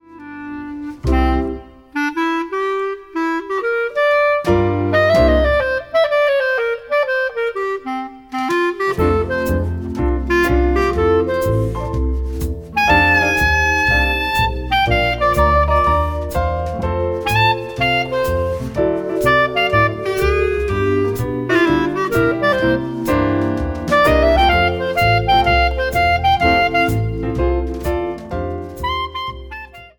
Vibraphone
Piano
Bass
Drums